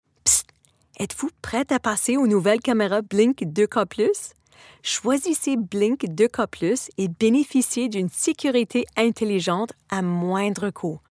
Commercial Sample (FR)
Clients describe her sound as engaging, distinct, and easy on the ears - like a trusted guide who knows how to have fun.
Broadcast-quality home studio | Fast, reliable turnaround | Friendly, professional, and directable